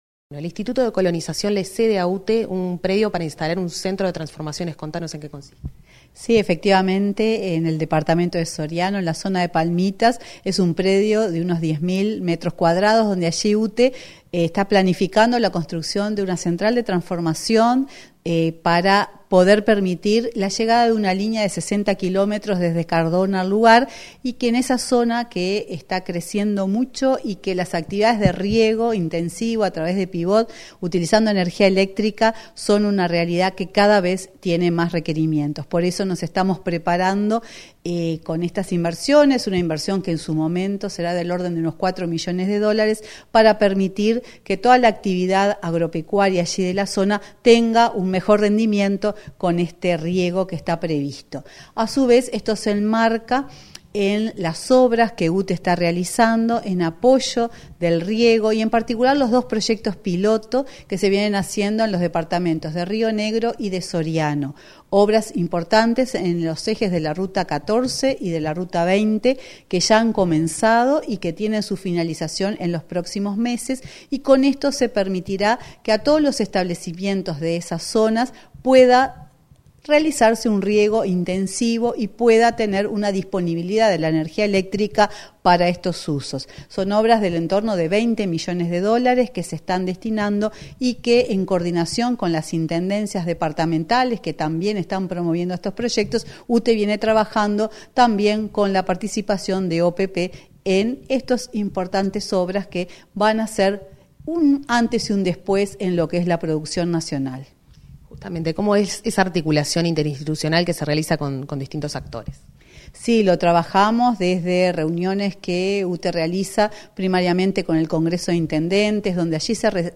Entrevista a presidenta de UTE, Silvia Emaldi, sobre futura estación de transformación